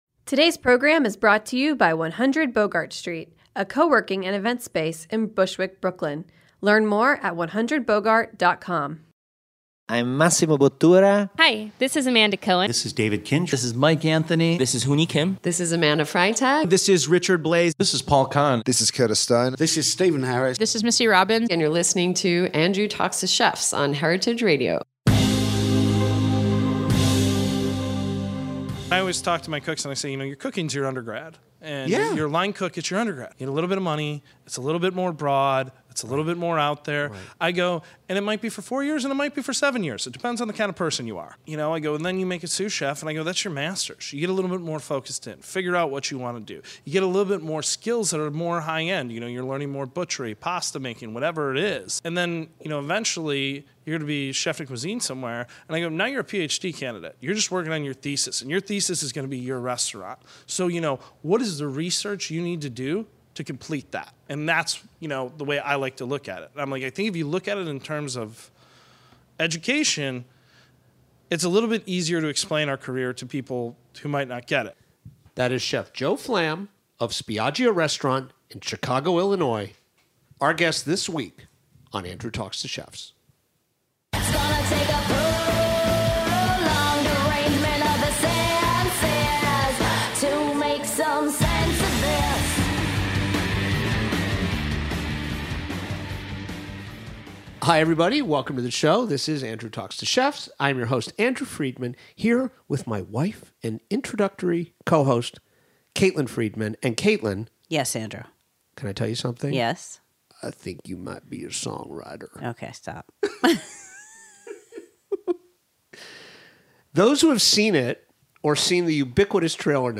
deep-dive biographical interview